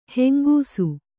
♪ hengūsu